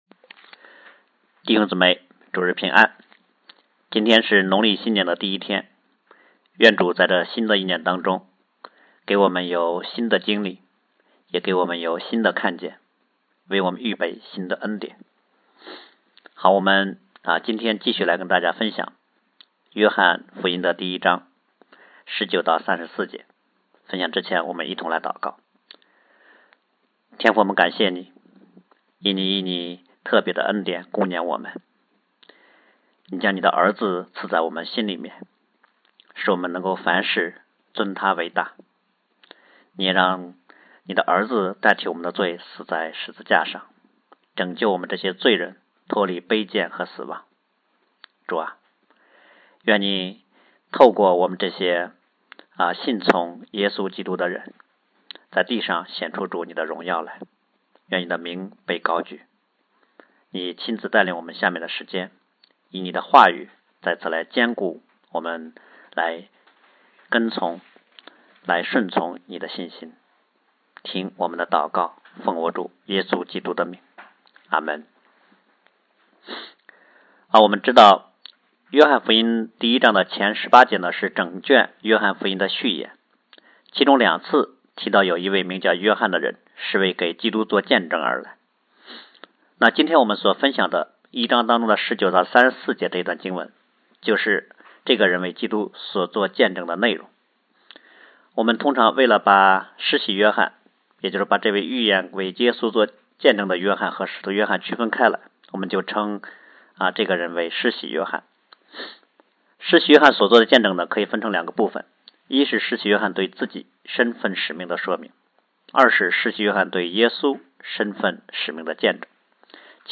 看哪，神的羔羊，除去世人罪孽的——2013年2月10日主日讲章